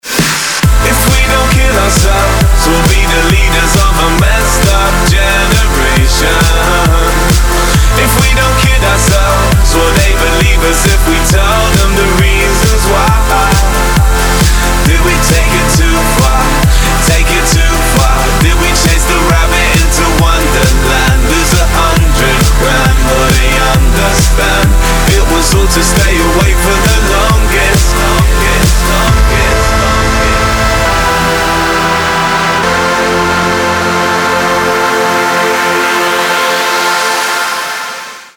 britského speváka a rappera